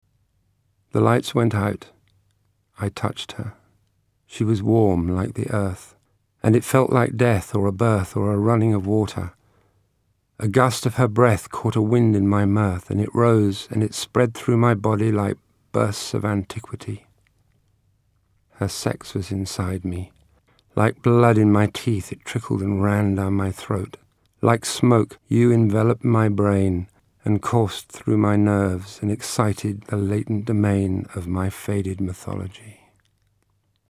POETRY READINGS
Lover (page 54) read by Hayley Mills